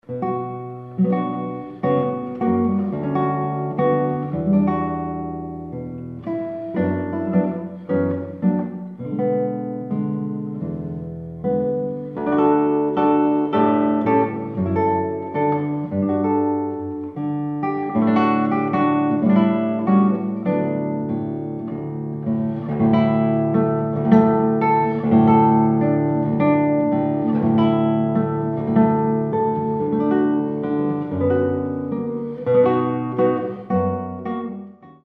solo guitar
The recording quality is rich and resonant